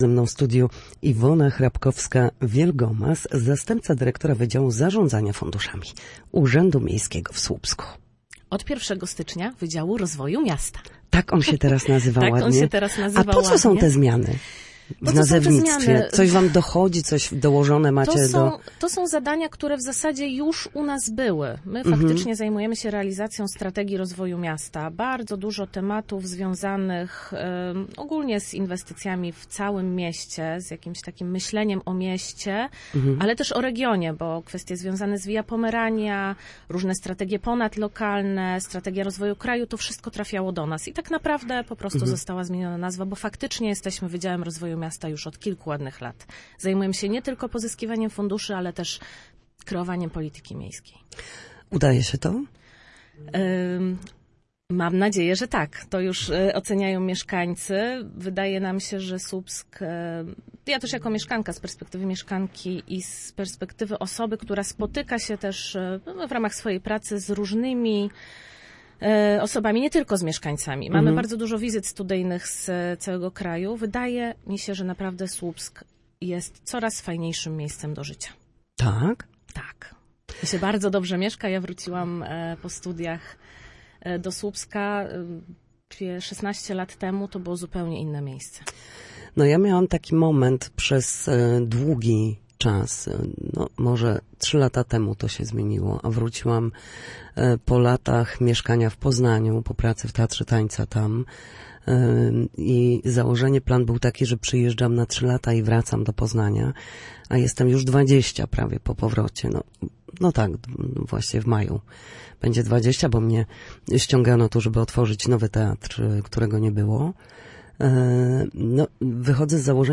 Na naszej antenie podsumowała miniony rok.